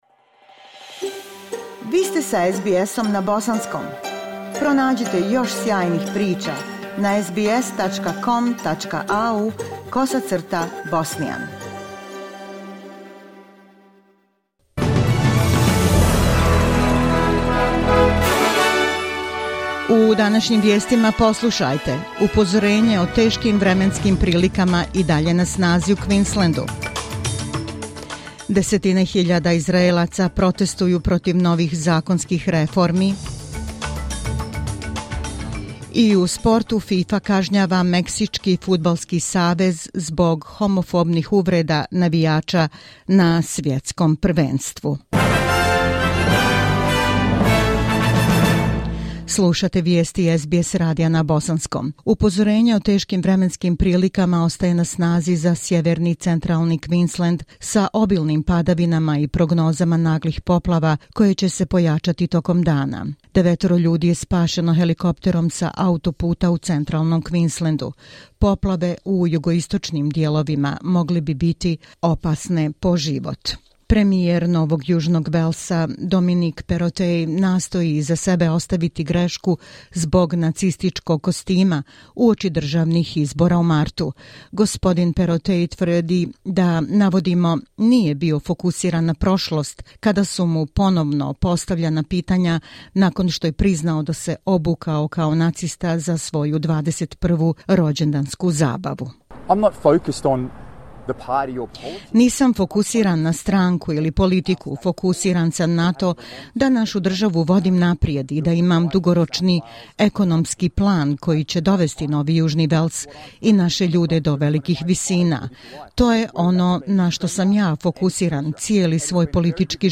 SBS radio news in the Bosnian language.